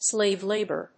アクセントsláve lábor